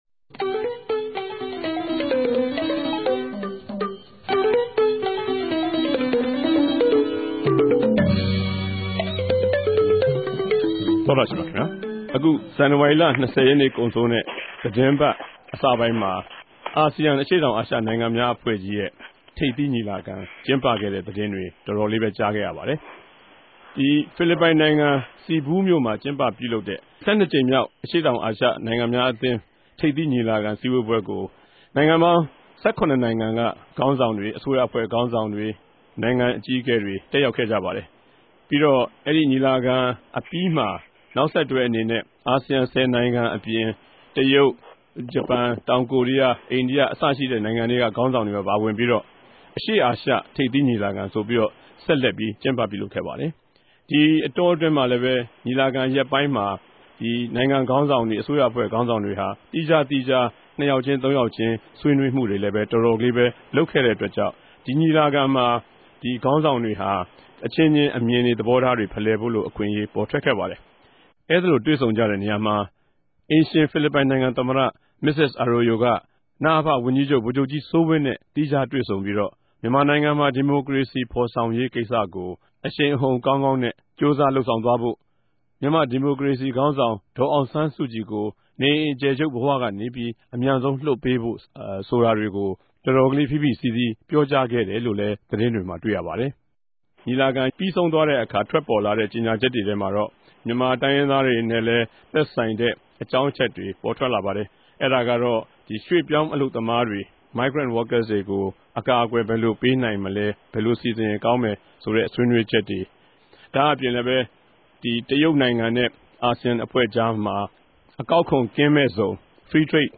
တပတ်အတြင်း သတင်းသုံးသပ်ခဵက် စကားဝိုင်း (၂၀၀၇ ဇန်နဝၝရီလ ၂၁ရက်)